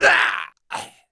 Sound / sound / monster / barbarian_bow / dead_1.wav
dead_1.wav